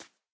hop4.ogg